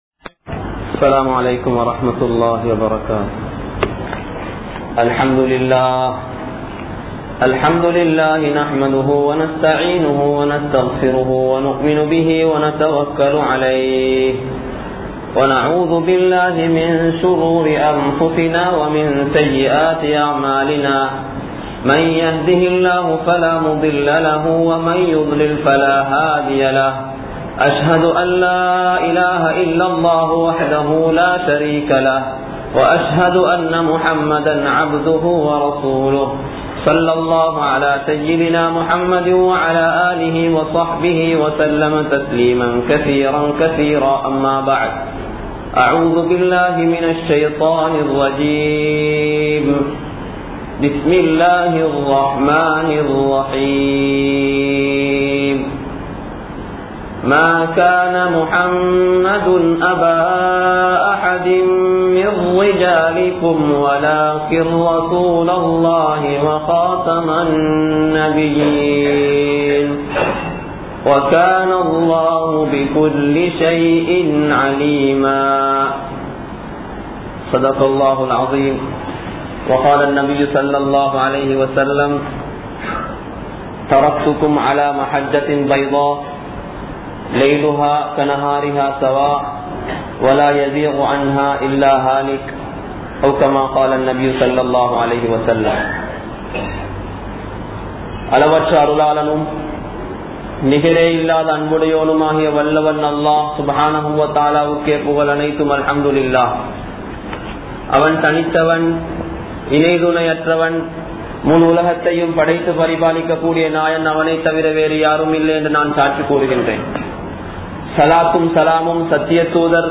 Nabimaarhalin Muththirai Nabi(SAW)Avarhal (நபிமார்களின் முத்திரை நபி(ஸல்)அவர்கள்) | Audio Bayans | All Ceylon Muslim Youth Community | Addalaichenai
Colombo 06,Kirulapana, Thaqwa Jumua Masjith